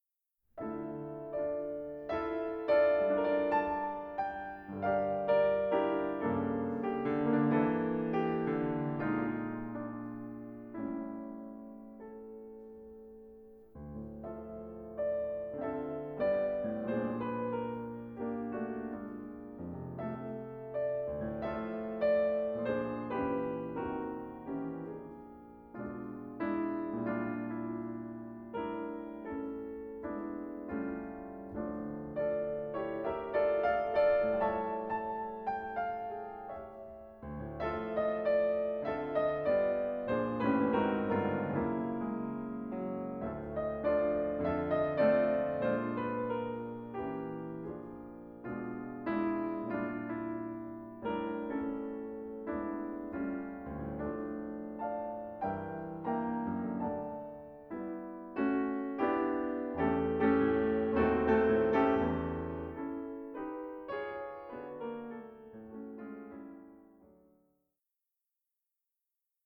these solo piano works, both charming and imposing